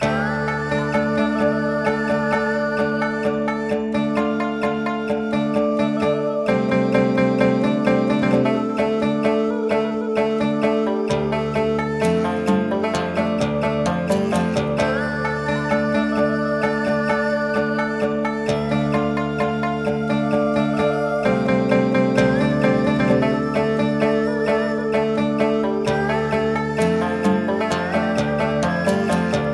描述：完整的管弦乐
Tag: 130 bpm Orchestral Loops Guitar Acoustic Loops 4.97 MB wav Key : D